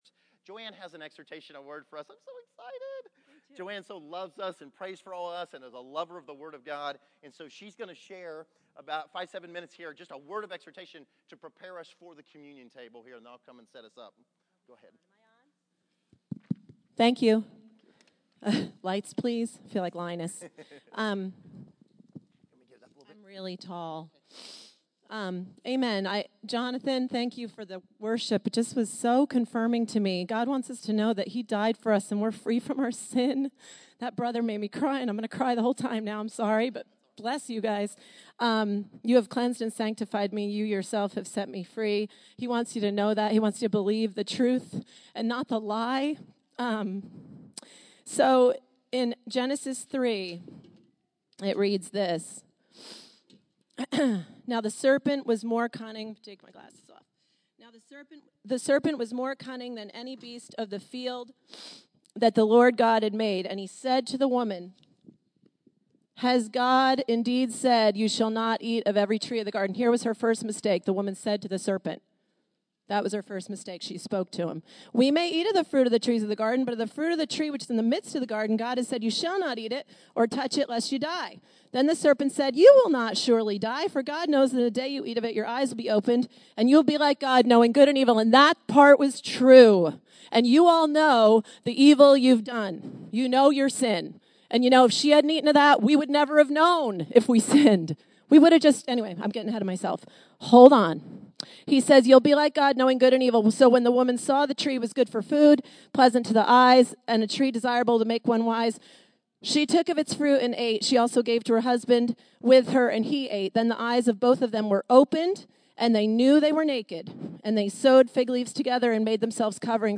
Exhortation
Category: Encouragements